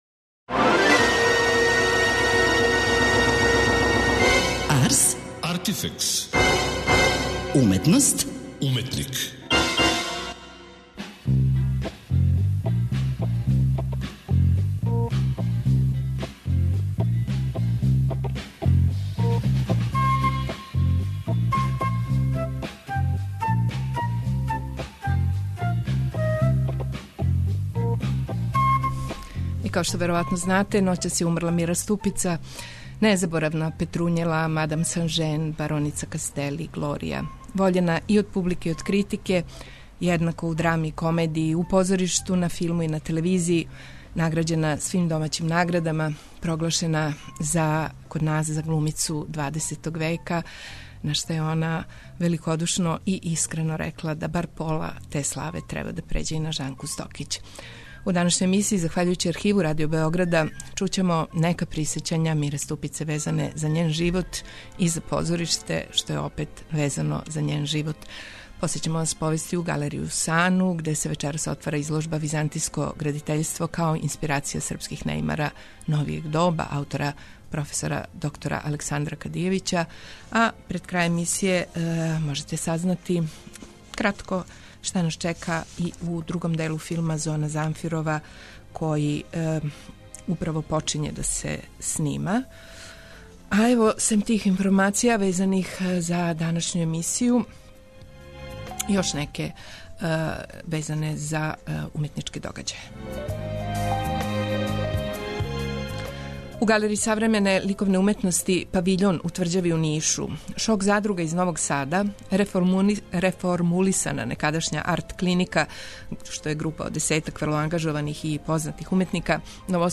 У данашњој емисији, захваљујући Архиву Радио Београда, чућемо нека присећања Мире Ступице везана за њен живот и позориште.